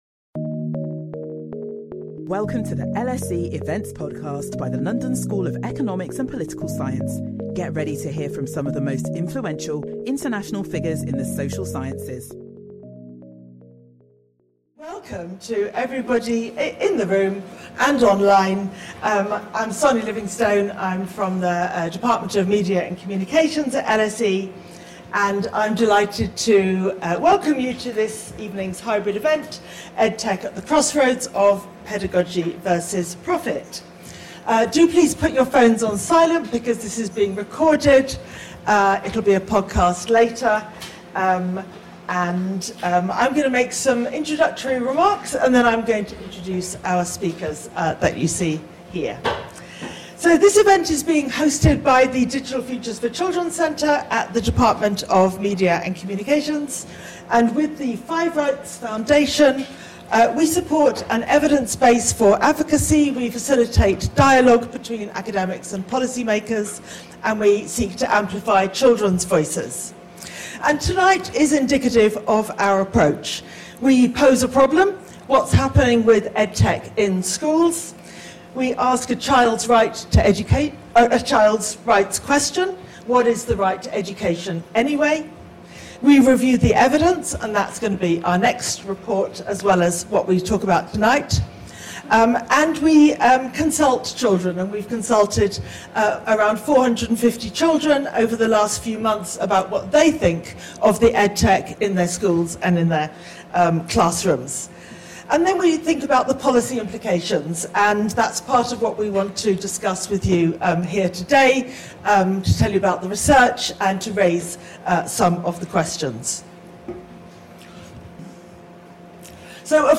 This lecture draws on research from the Digital Futures for Children centre and the 5Rights Foundation’s Better EdTech Futures for Children project.